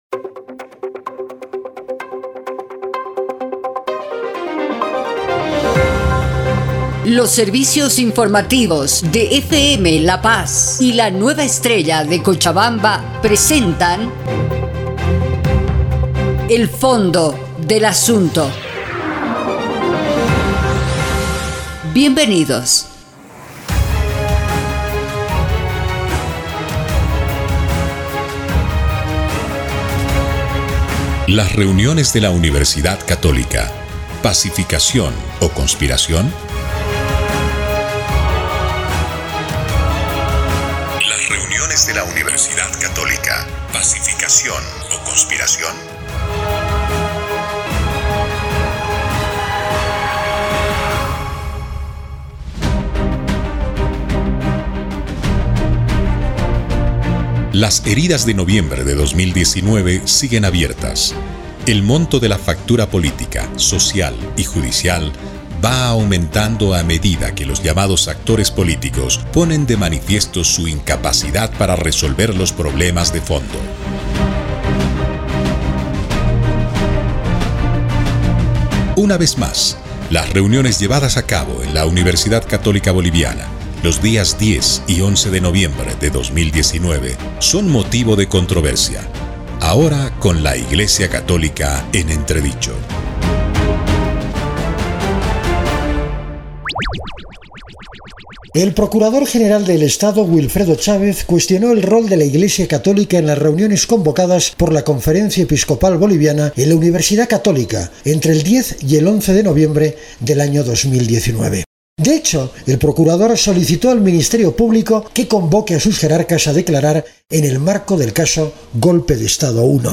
Un programa de reportajes